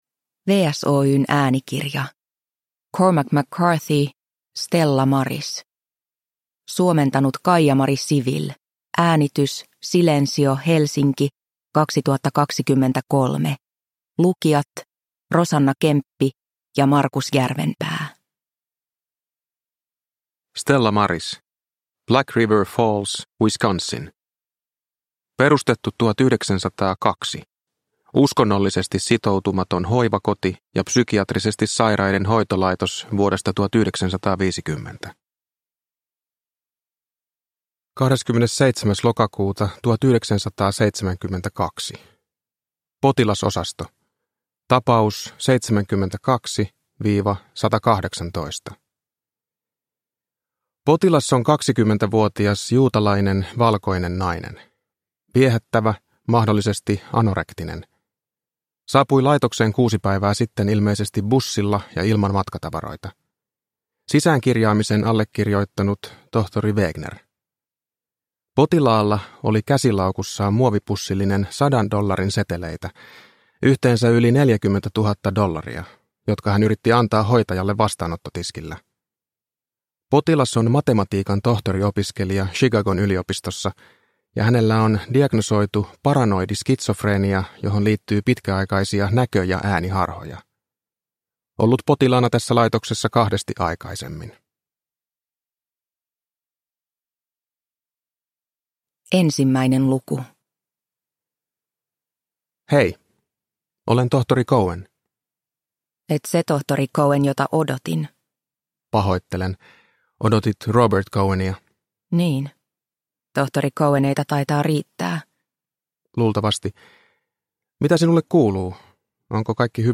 Stella Maris – Ljudbok – Laddas ner